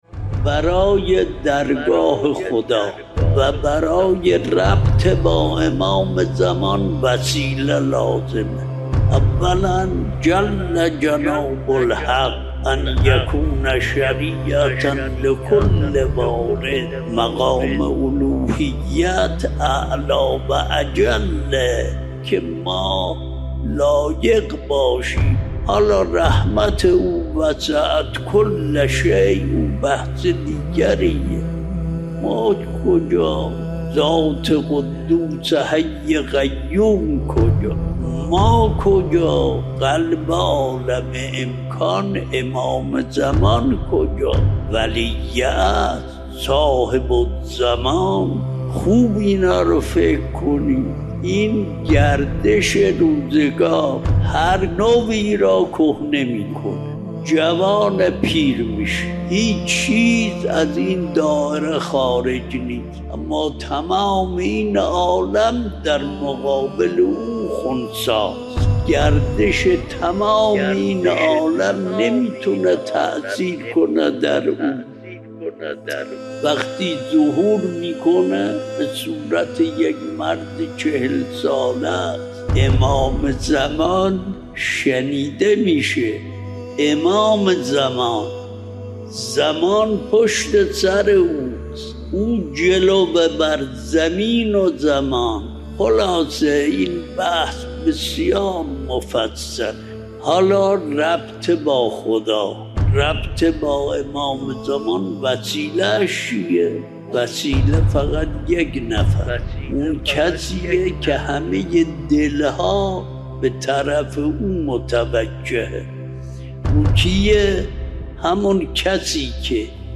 آیت‌الله وحید خراسانی درباره مقام حضرت زهرا(س) می‌گوید: برای ارتباط با درگاه خدا و برای ربط با امام زمان(عج) وسیله لازم است.